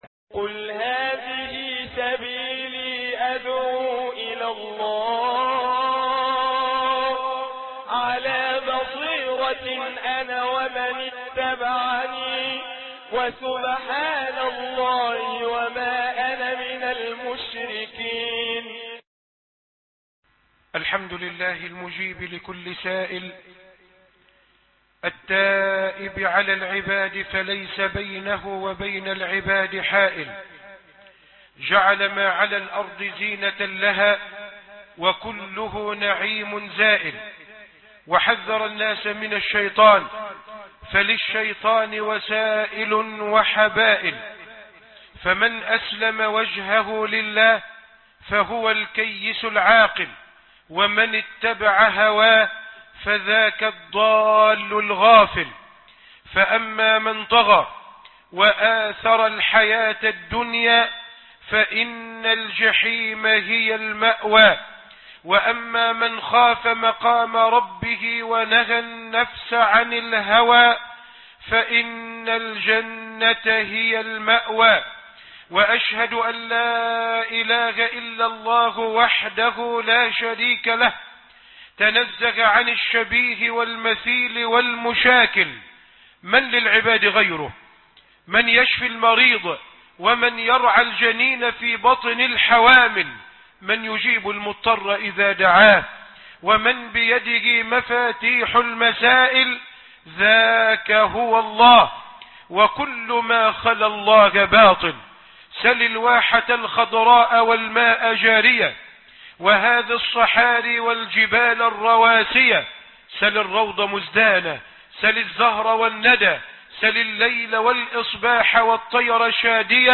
تذكرة لأولى الألباب - خطب الجمعه بمسجد التابعيين ببنها